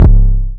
808 (BurrberrySlap).wav